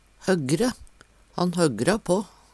DIALEKTORD PÅ NORMERT NORSK høggre le høgt og støyande Infinitiv Presens Preteritum Perfektum høggre høggrar høggra høggra Eksempel på bruk Han høggra på.